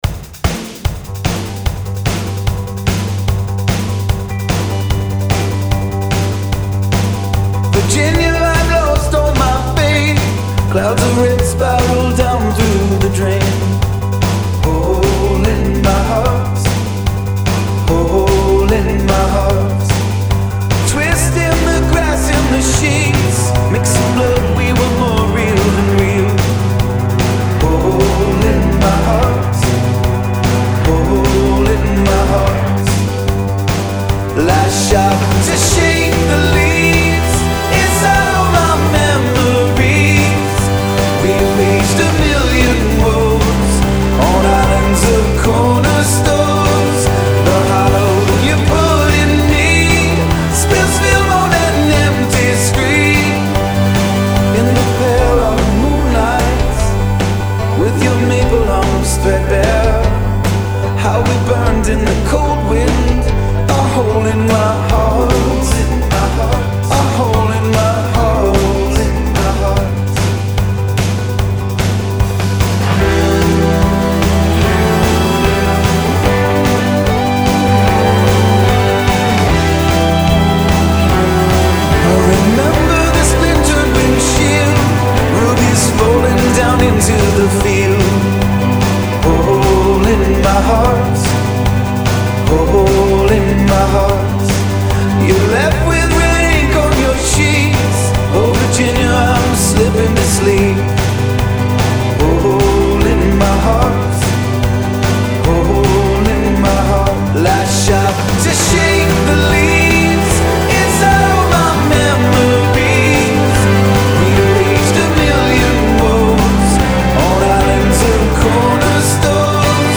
muscular yet sensitive uptempo rocker